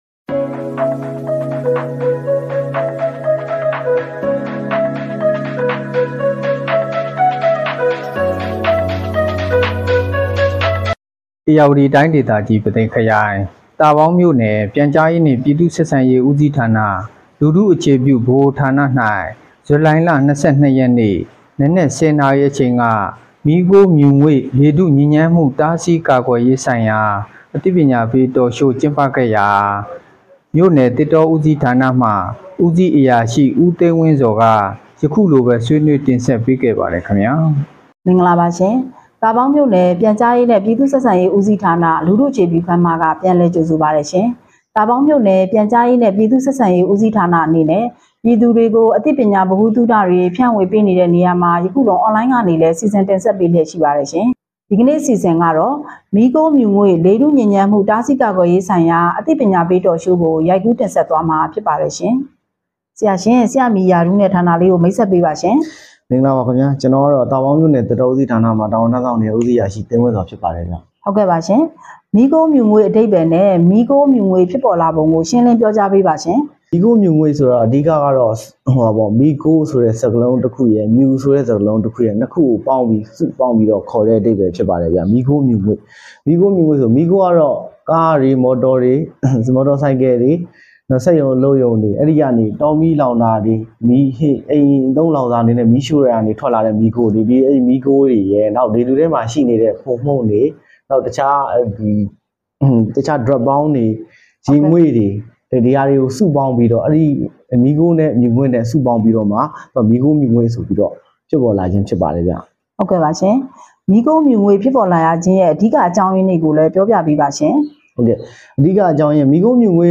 လူထုအခြေပြုဗဟိုဌာနတွင် မီးခိုးမြူငွေ့ညစ်ညမ်းမှုတားဆီးကာကွယ်ရေးဆိုင်ရာ အသိပညာပေး Talk Show ကျင်းပ